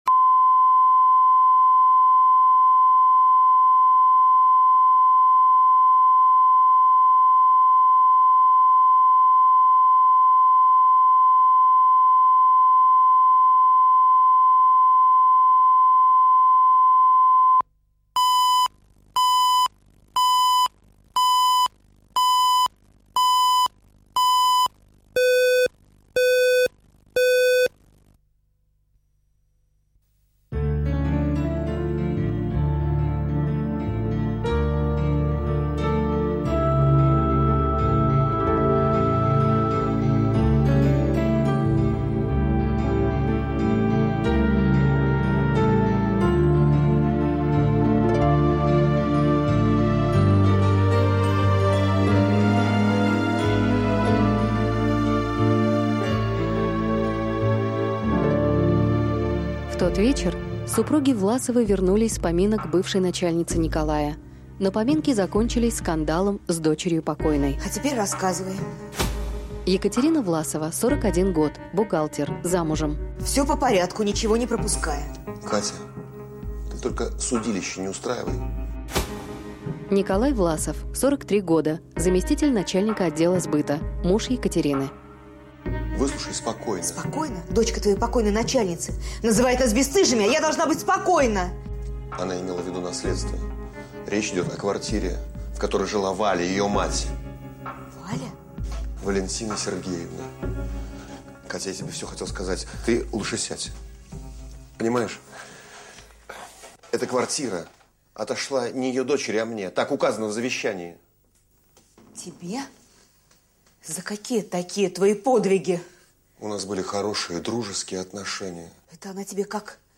Аудиокнига Щедрый подарок | Библиотека аудиокниг